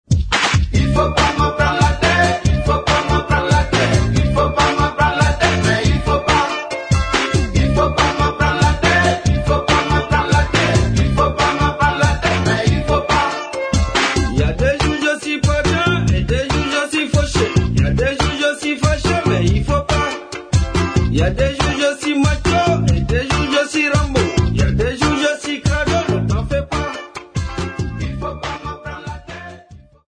Popular music--Africa, West
Reggae music
Cassette tape